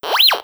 Mine Detector - Updated mine detector audio from -20db to -2db
metal_detector.wav